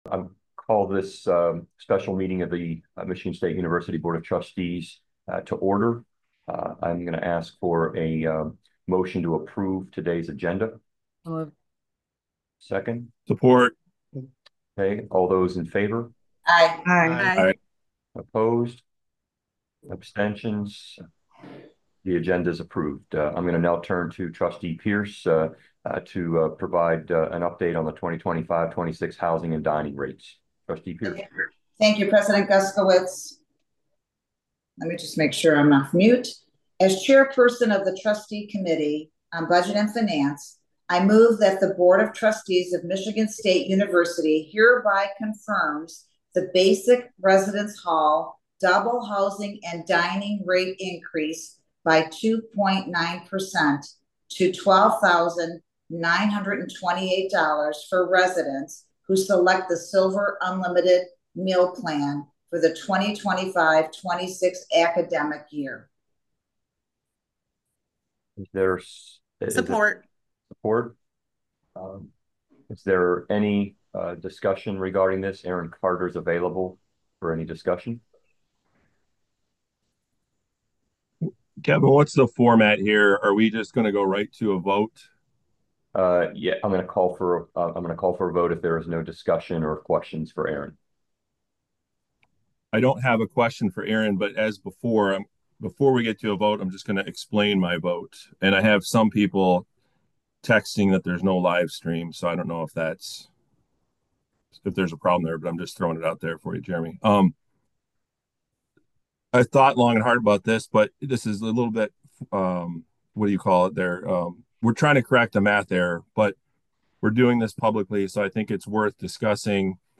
Where: Zoom